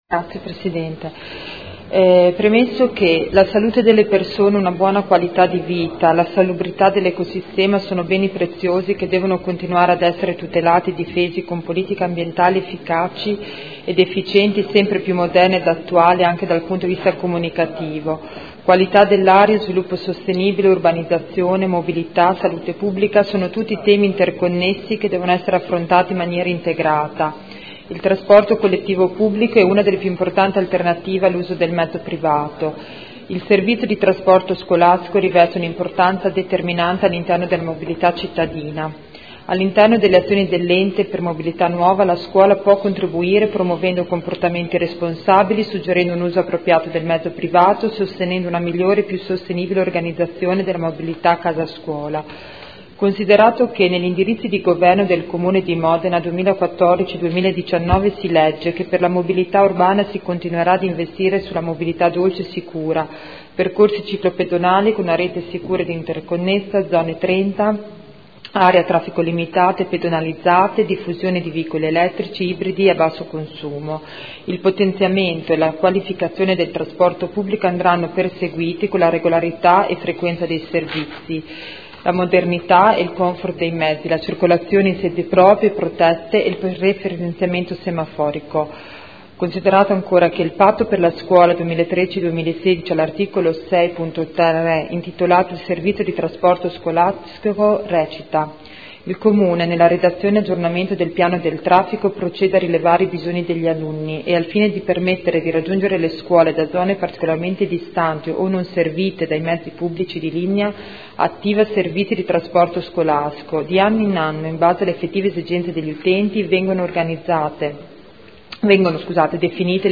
Grazia Baracchi — Sito Audio Consiglio Comunale
Seduta del 09/02/2015.